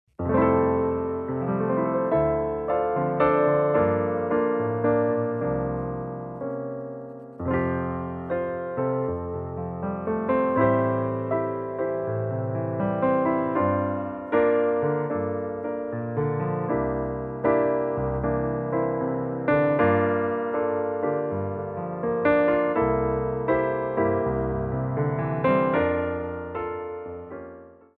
Piano Arrangements
SLOW TEMPO